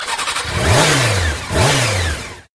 enthiran-car-start_24833.mp3